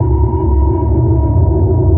sonarPingSuitCloseShuttle3.ogg